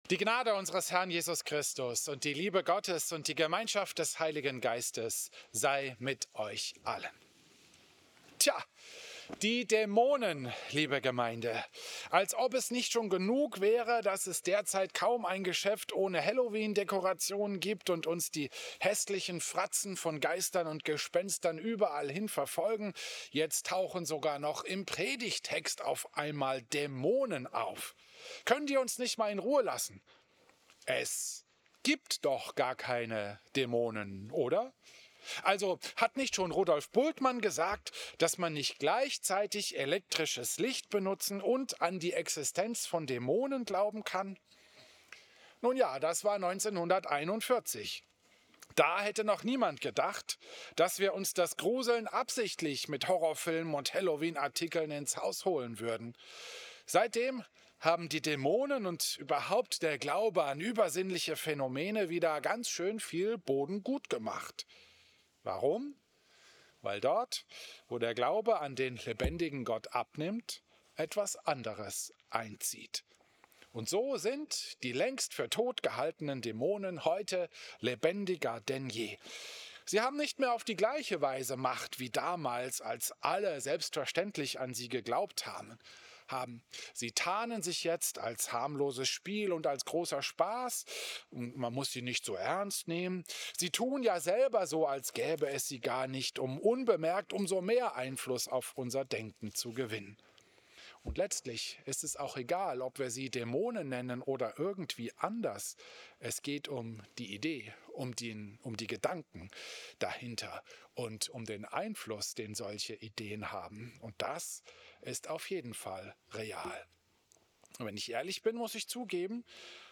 Christus-Pavillon Volkenroda, 19. Oktober 2025
Predigten